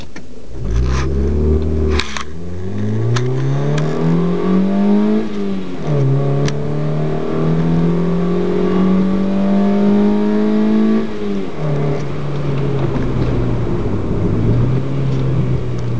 0-100 km/h ac JR